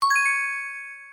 sfx_sparkle.ogg